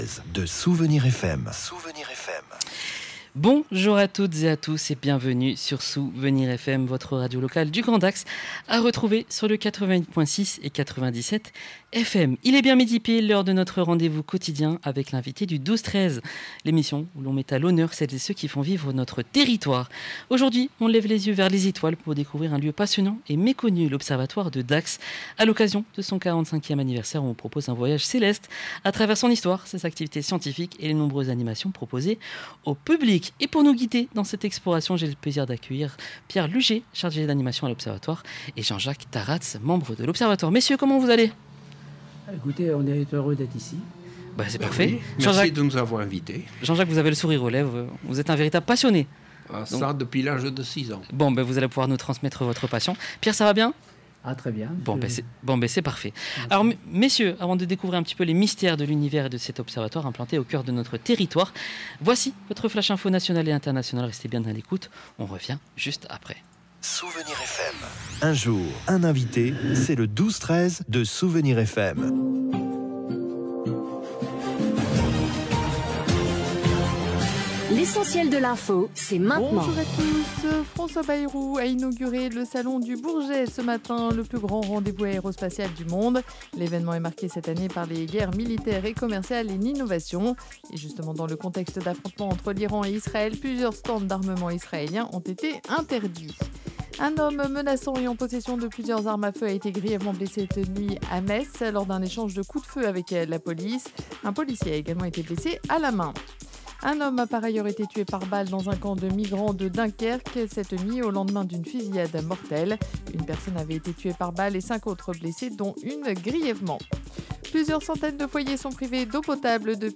Ce midi, SOUVENIRS FM recevait l’Observatoire de Dax